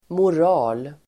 Uttal: [mor'a:l]